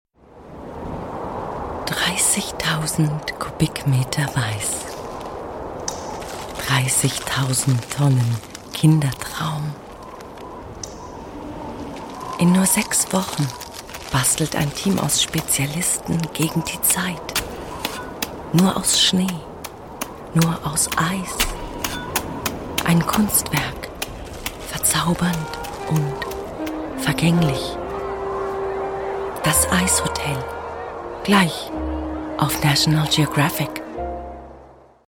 deutsche Sprecherin mit warmer, sinnlicher, sehr wandlungsfähiger Stimme, Stimmlage mittel / tief,
Sprechprobe: Werbung (Muttersprache):